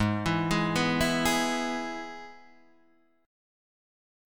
Abm#5 chord